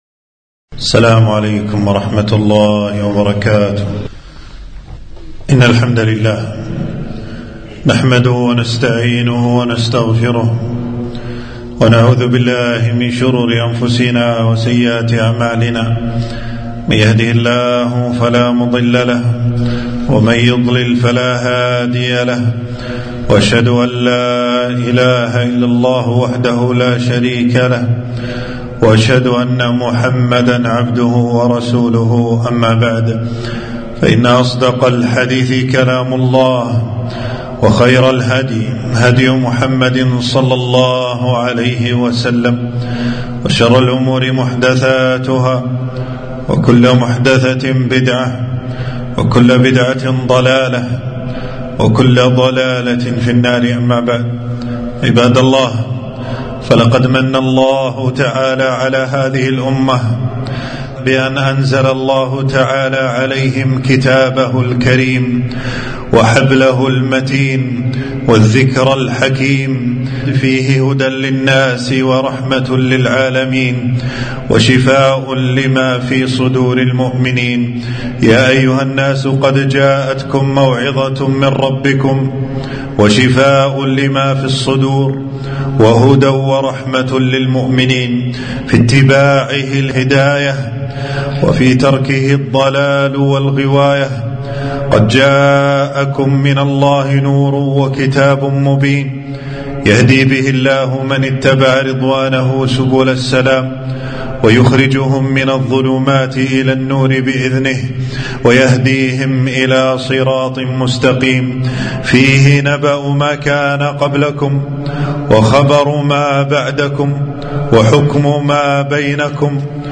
خطبة - اقرأوا القرآن